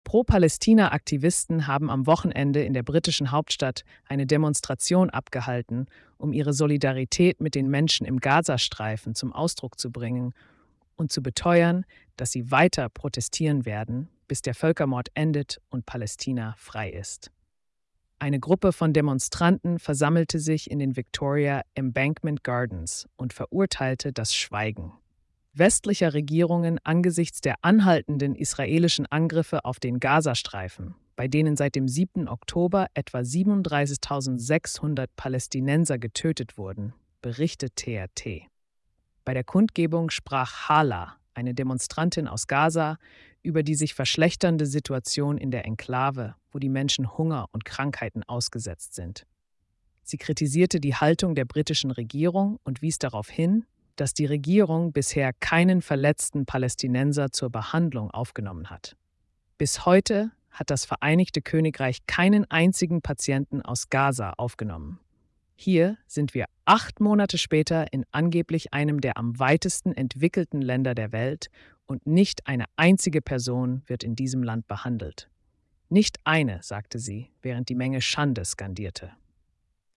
Pro-Palästina-Aktivisten haben am Wochenende in der britischen ‌Hauptstadt eine Demonstration abgehalten, um ihre Solidarität mit den Menschen im Gazastreifen zum Ausdruck zu bringen und zu beteuern, dass sie‌ weiter‌ protestieren werden, bis „der‍ Völkermord endet und Palästina frei ist.“